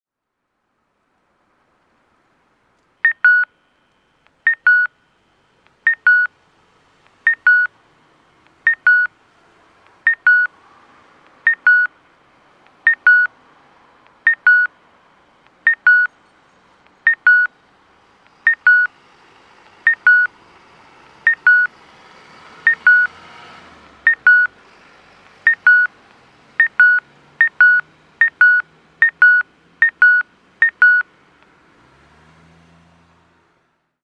大道小学校南(大分県大分市)の音響信号を紹介しています。